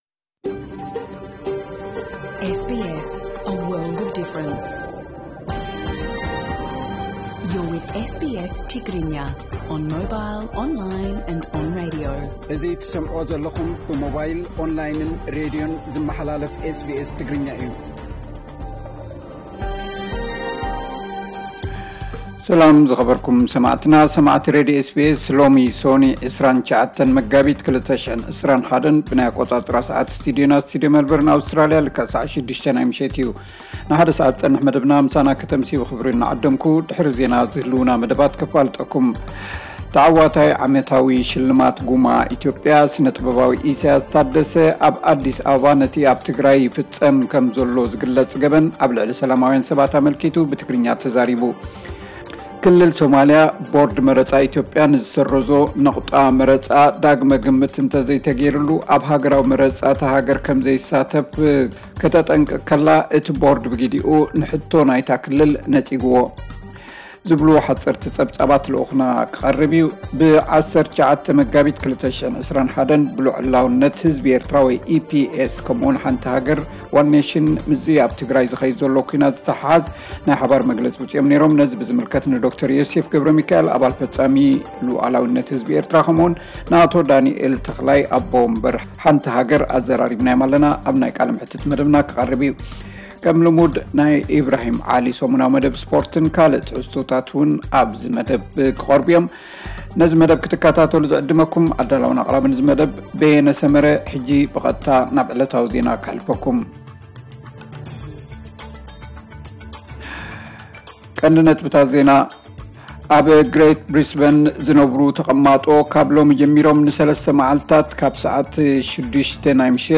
ዕለታዊ ዜና 29 መጋቢት 2021 SBS ትግርኛ